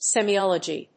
音節se・mei・ol・o・gy 発音記号・読み方
/sìːmɑɪάlədʒi(米国英語), sèmiɑɪάlədʒi(英国英語)/